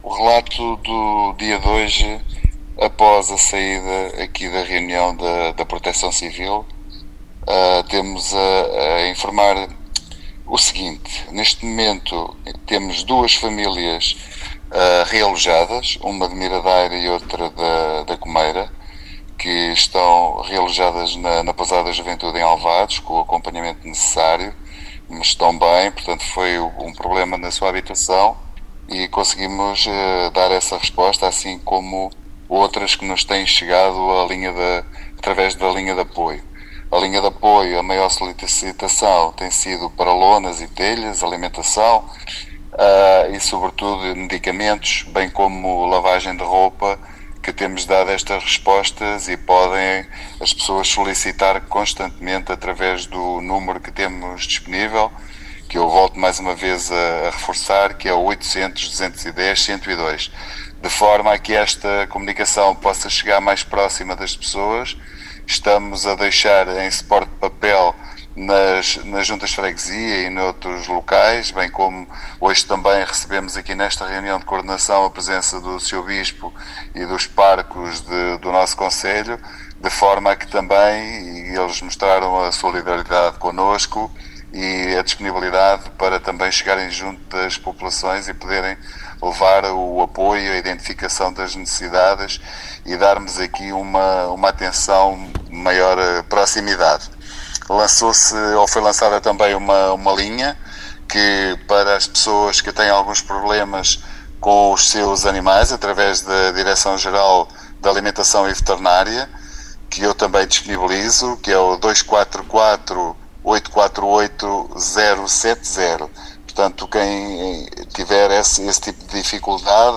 No habitual ponto de situação diário aos microfones da Rádio Dom Fuas e de O Portomosense, o responsável autárquico referiu que a reunião da Proteção Civil contou também com a presença do Bispo de Leiria-Fátima, D. José Ornelas, que está de visita à Unidade Pastoral de Porto de Mós, bem como dos padres da referida Unidade, tendo ficado assegurado que a Diocese e as paróquias irão continuar a colaborar com a Câmara no sentido que a informação e a ajuda possam chegar ao maior número de pessoas afetadas pela depressão Kristin.